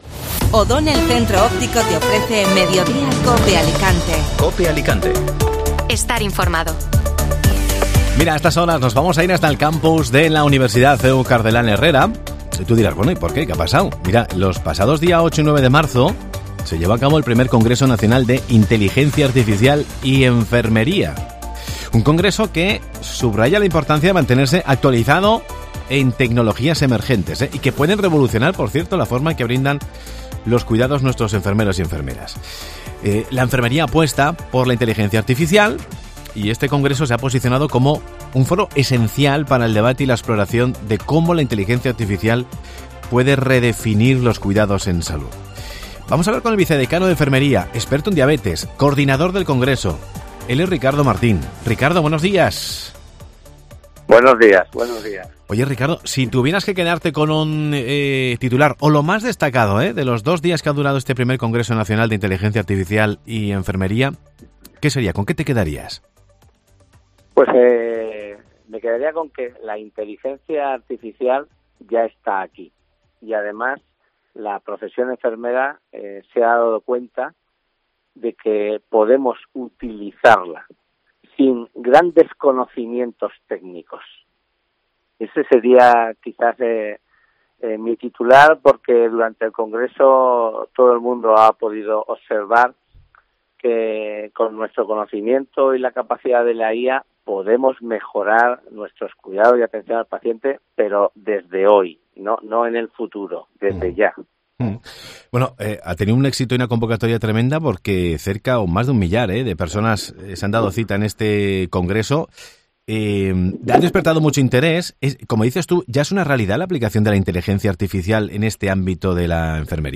AUDIO: El Campus de la universidad CEU Cardenal Herrea aborda el uso de la Inteligrancia Artificial en la enfermería. Entrevista